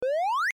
Play, download and share Fischio salto original sound button!!!!